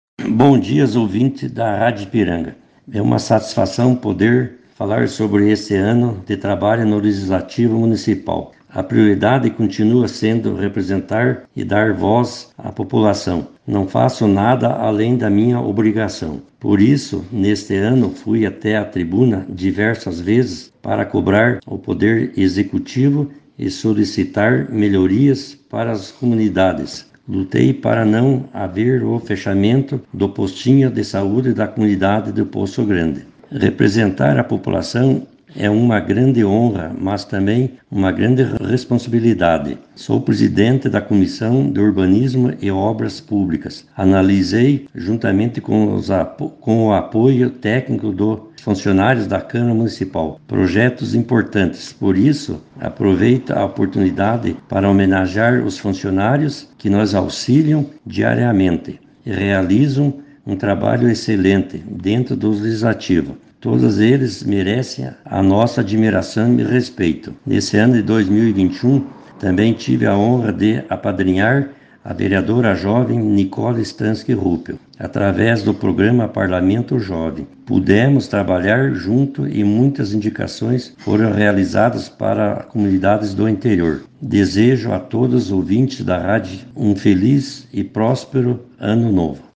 Ouça a fala do vereador Rogério